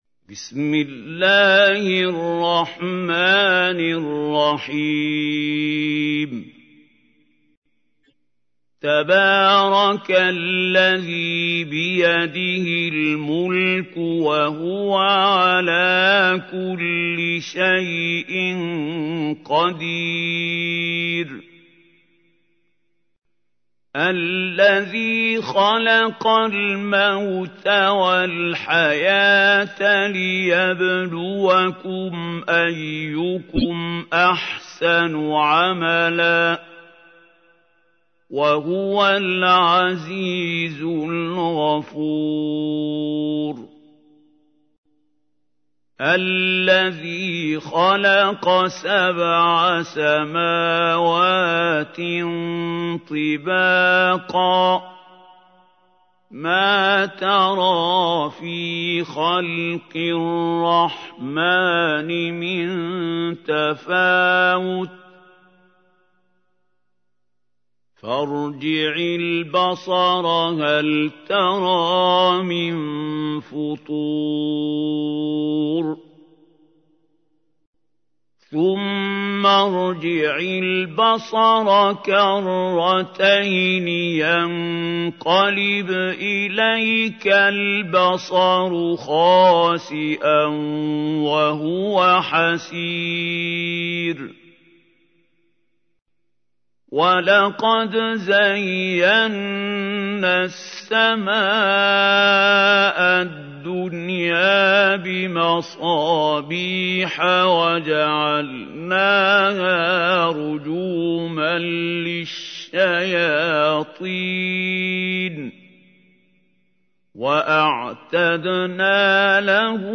تحميل : 67. سورة الملك / القارئ محمود خليل الحصري / القرآن الكريم / موقع يا حسين